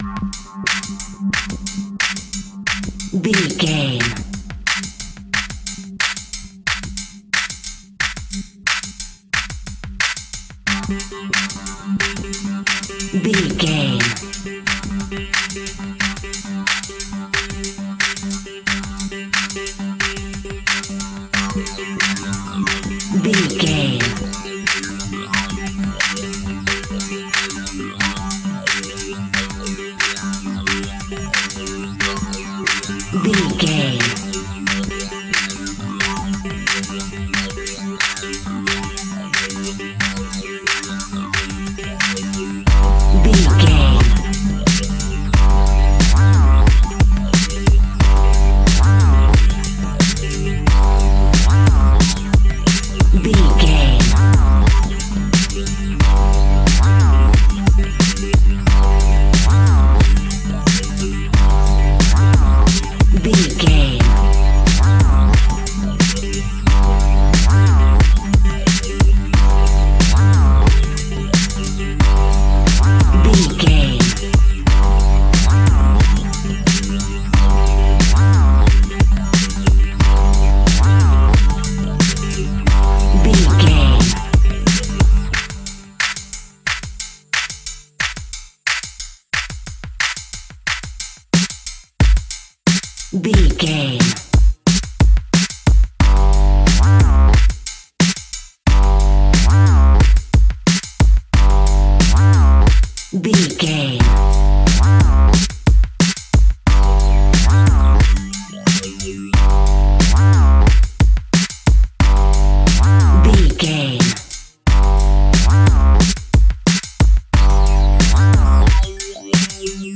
Aeolian/Minor
hip hop instrumentals
funky
groovy
east coast hip hop
electronic drums
synth lead
synth bass